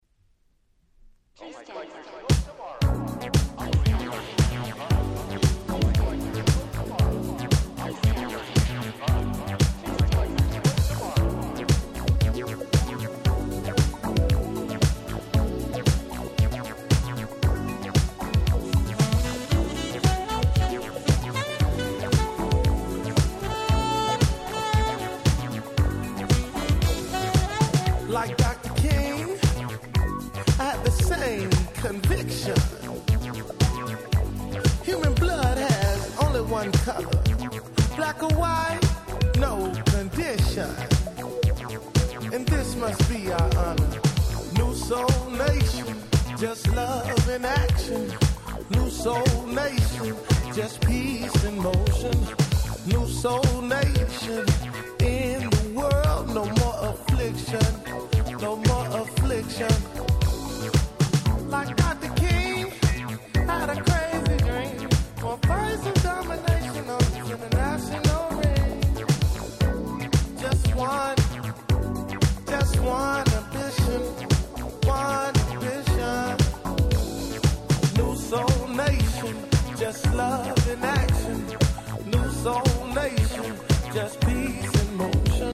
聴いていてワクワクしてしまう様な素敵なBoogieやModern Soulがてんこ盛りです！！